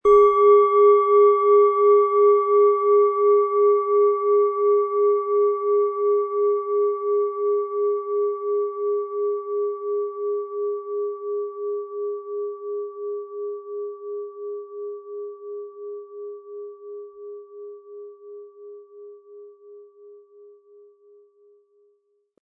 Planetenschale® Erfinde Dich neu & Altes aufgeben mit Uranus, Ø 12,5 cm, 320-400 Gramm inkl. Klöppel
Durch die traditionsreiche Fertigung hat die Schale vielmehr diesen kraftvollen Ton und das tiefe, innere Berühren der traditionellen Handarbeit
MaterialBronze